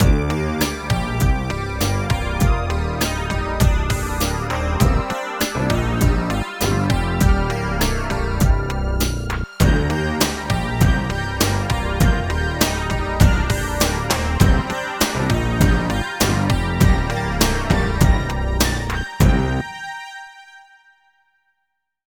12 LOOP B -L.wav